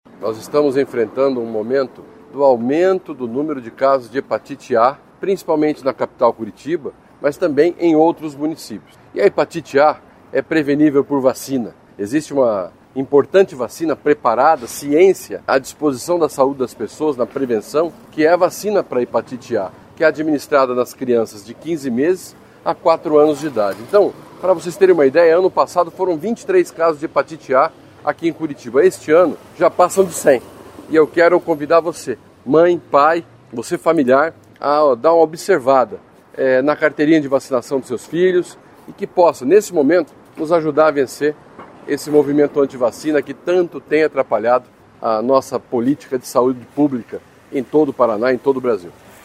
Sonora do secretário da Saúde, Beto Preto, sobre o aumento de casos de hepatite A no estado